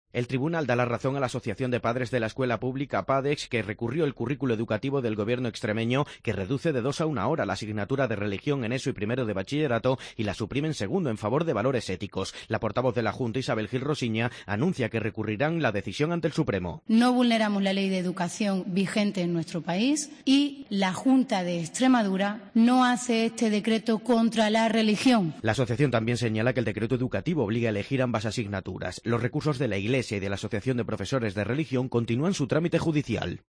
Escucha la crónica de COPE Mérida